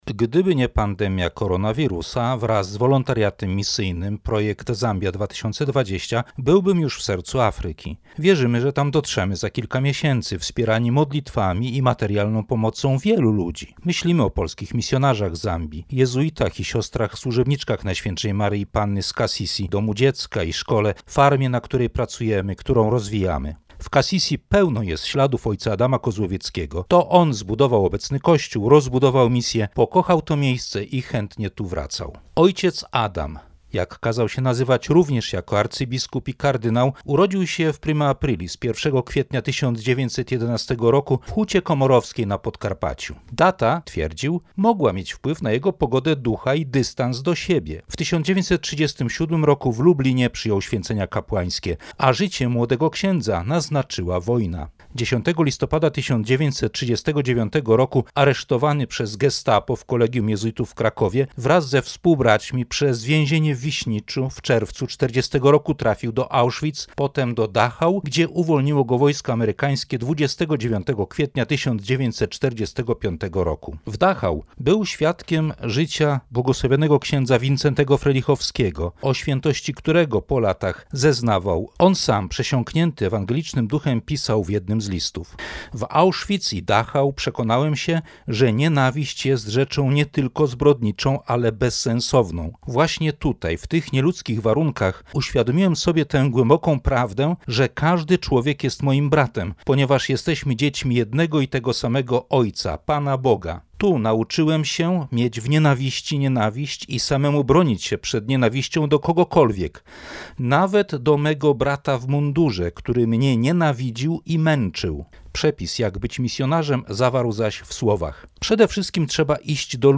Felieton